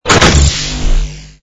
ui_equip_mount05.wav